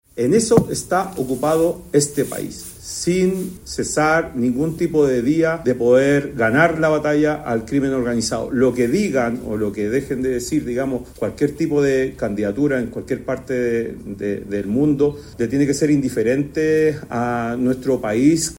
En ese sentido, en entrevista con T13 Radio, el ministro del Interior, Álvaro Elizalde, señaló que las declaraciones de Paz son un “incentivo para que se sigan cometiendo delitos y contrabando”, palabras que fueron secundadas por el subsecretario del Interior, Víctor Ramos, quien recalcó la fuerte lucha en contra del crimen organizado y los delitos transnacionales de Chile.